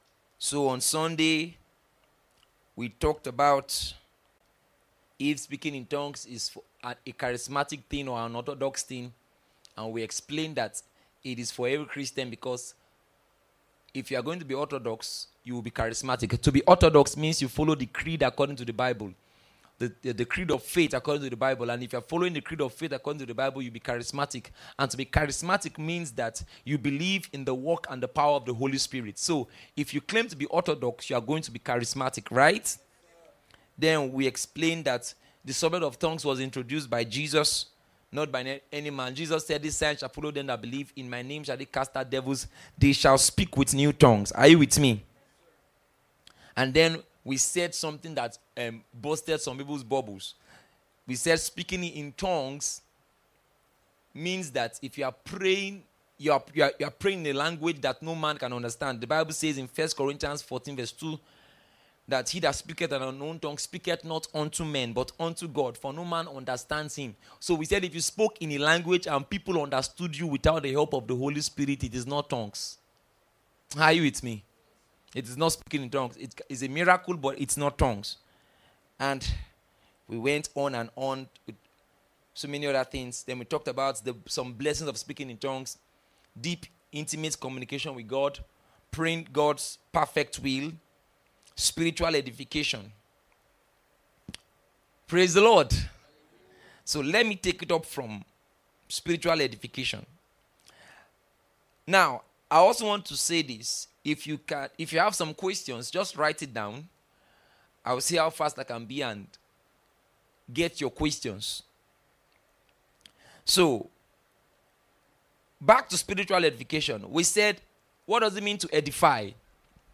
This Tongues Matter - Q&A.MP3